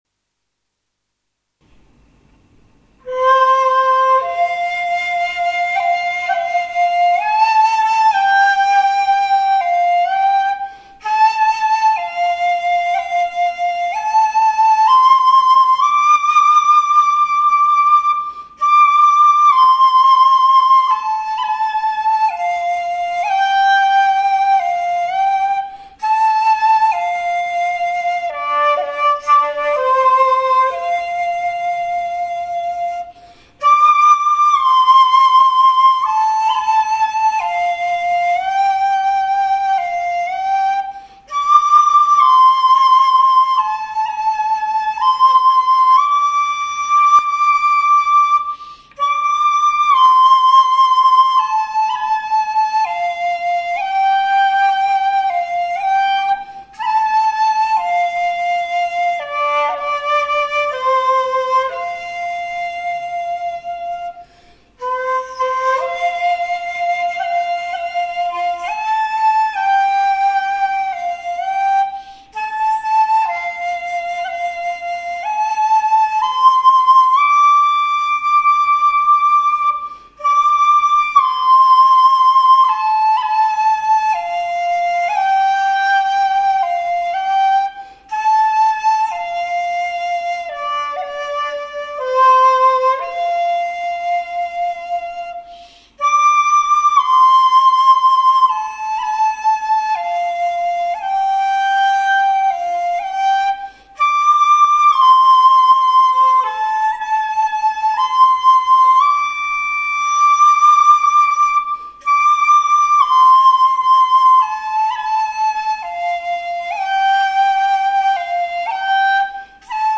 尺八練習曲
蛍の光 　ok ２コーラス（高い音を出す練習です））